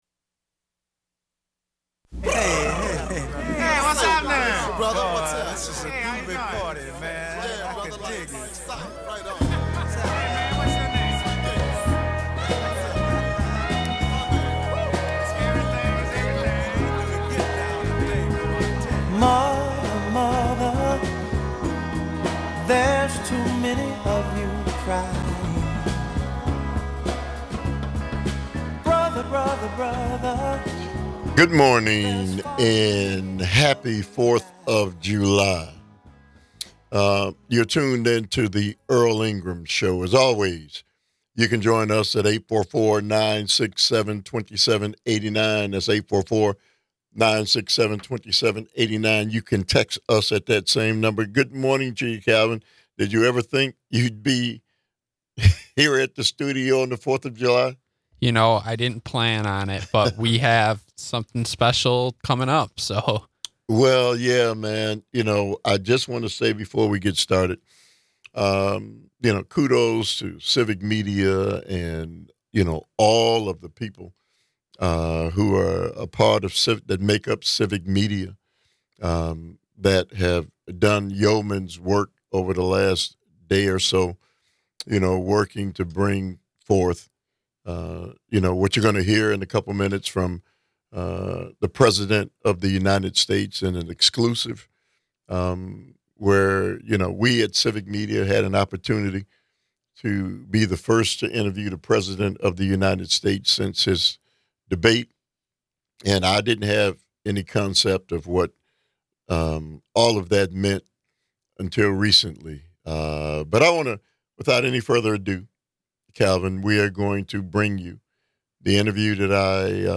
special broadcasts